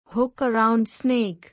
hook-around-snake.mp3